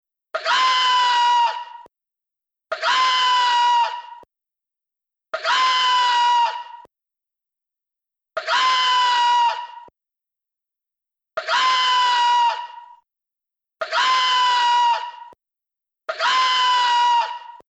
Casi por casualidad, encuentro la irritante grabación de un gallo y lo importo al proyecto con el objetivo de añadirle el dramatismo que le falta a mi interpretación:
gallo.mp3